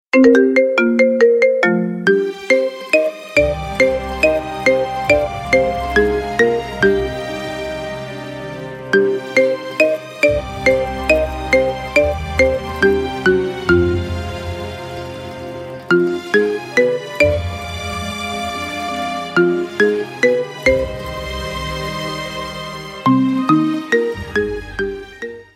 Стандартные рингтоны